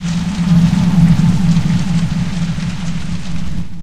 PixelPerfectionCE/assets/minecraft/sounds/mob/blaze/breathe1.ogg at mc116
breathe1.ogg